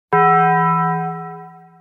Sound Effects
Taco Bell Bong